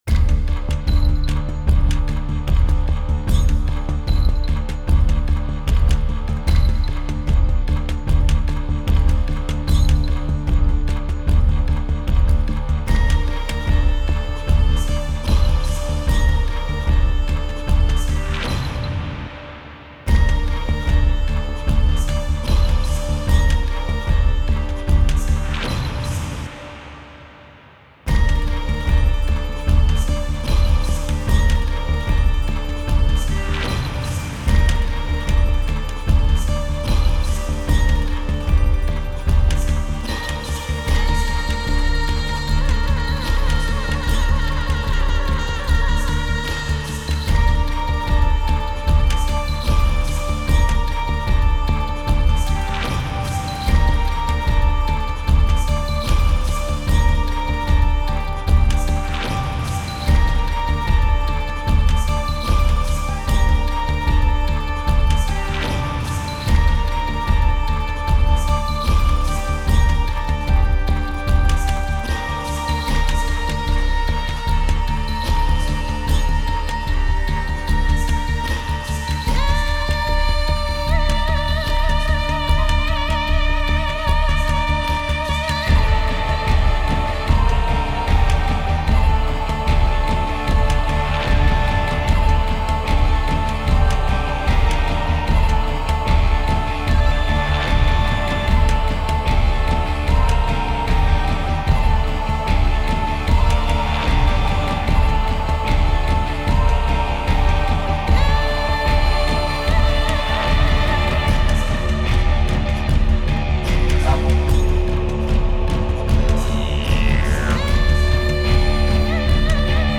Fantasy & Battle Background Music